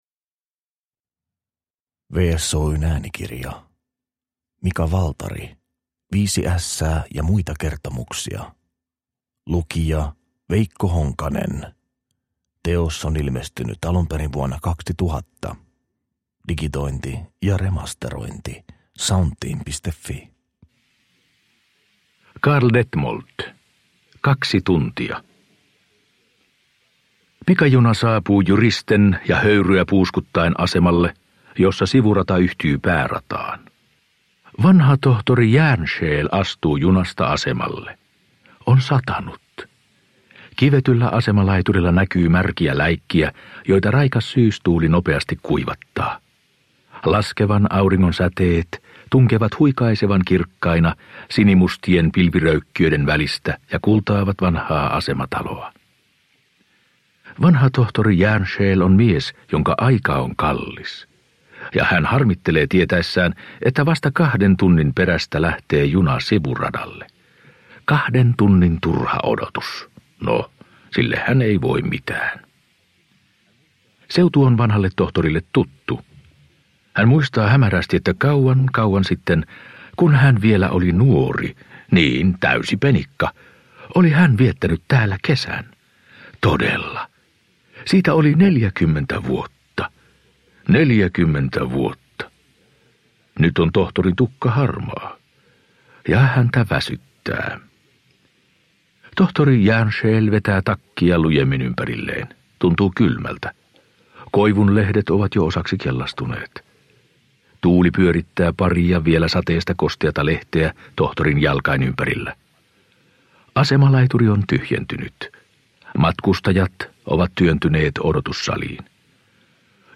Viisi ässää ja muita kertomuksia – Ljudbok – Laddas ner